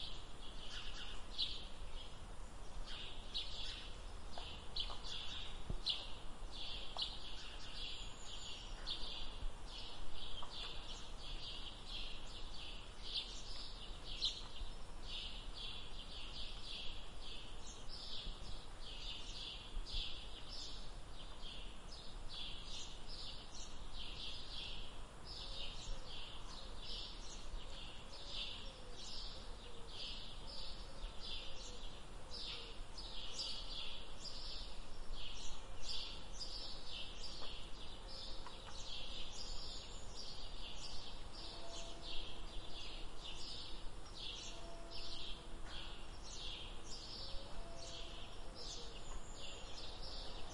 描述：low sound of footsteps on a wooden catwalk, with bird (Warbler, Sparrow, Coot, Starling) calls in background.
标签： ambiance birds fieldrecording nature walking
声道立体声